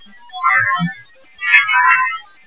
I took along my tape recorder and digital camera.
In the next file you will hear a man saying what I believe to be his full name.
Its a little whispery....but still fairly clear.